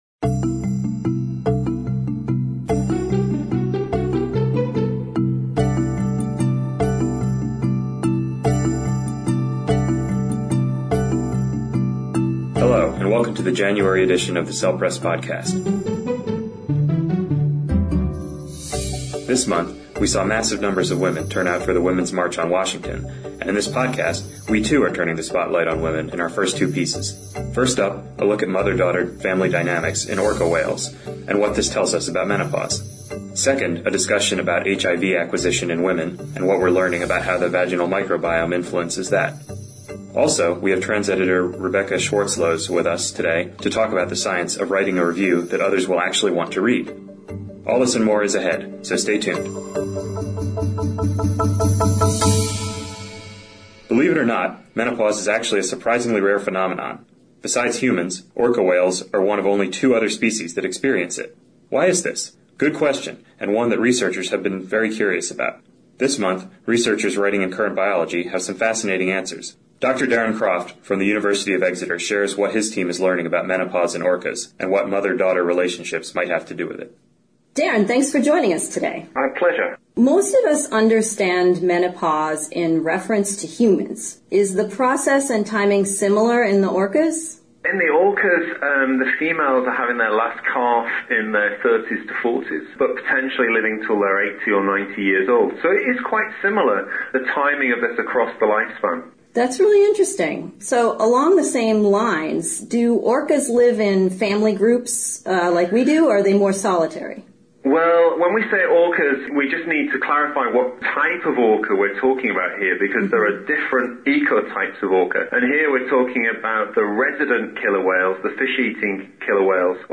Podcast Interview on Writing Review Articles (Bonus: Grandmother Orcas)